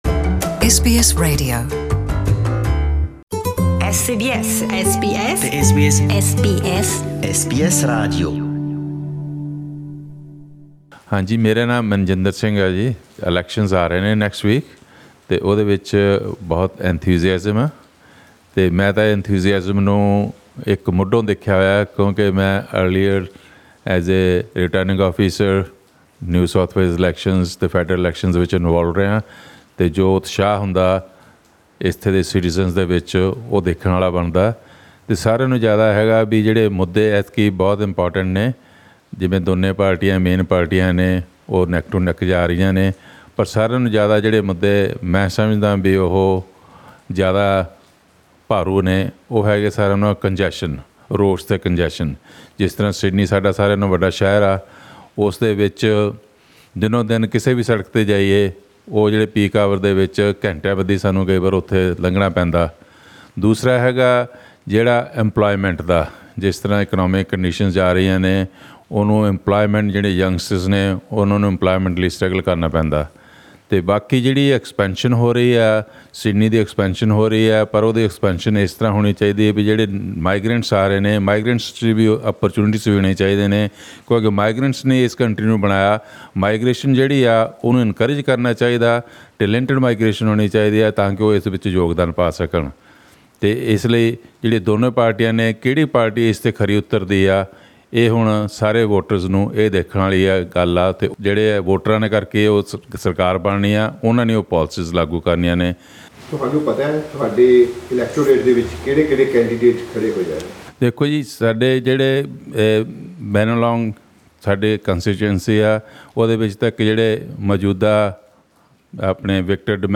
Here are some views from our listeners.